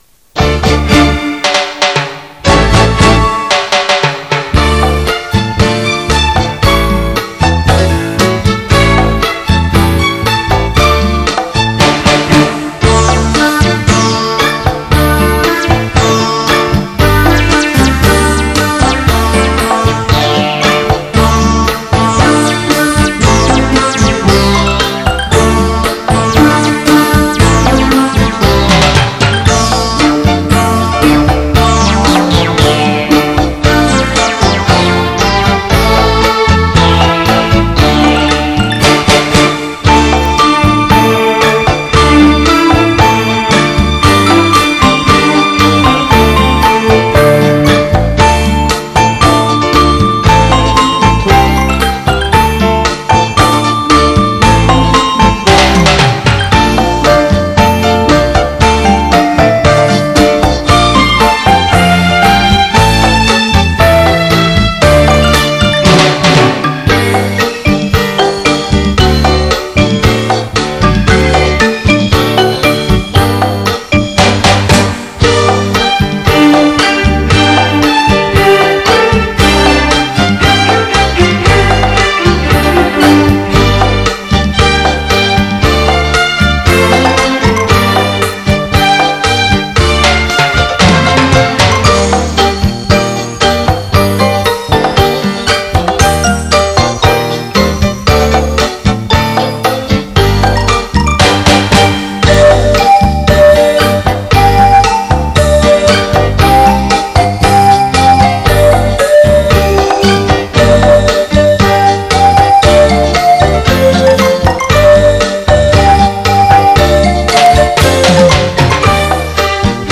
环绕立体声
伦巴
运用拉丁舞风的伦巴令曲子非常动感和活力